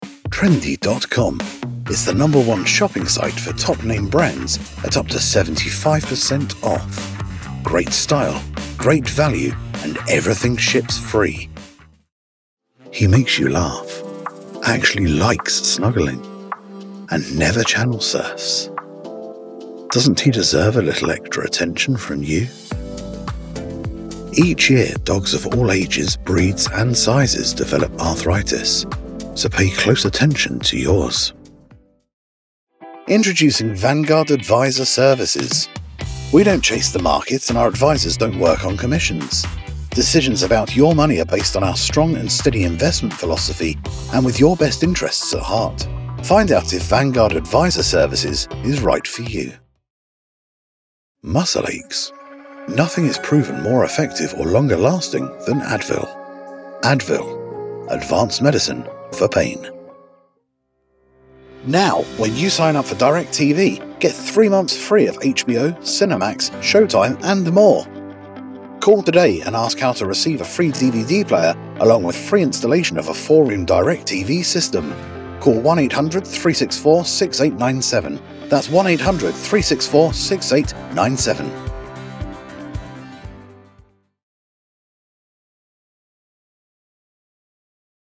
british rp | natural
COMMERCIAL 💸